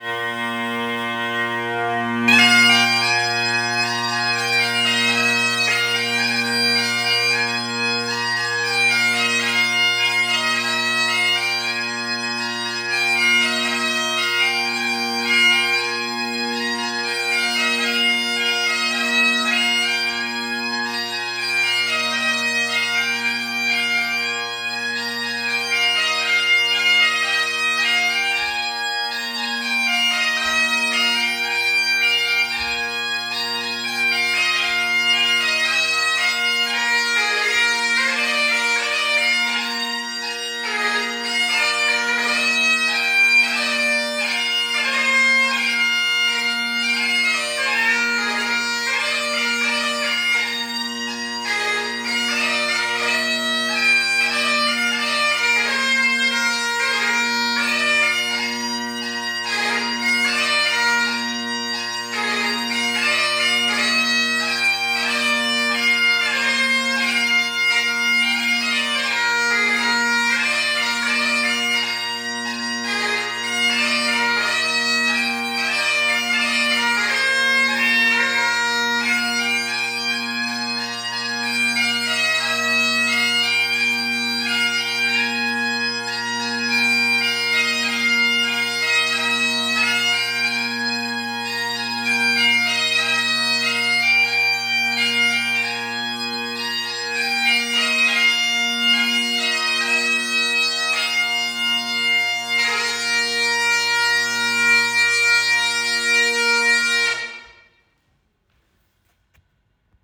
Säkkipillin ja poikkihuilun soittaja häihin, hautajaisiin, synttäreille.
Solisti
cover-kappaleita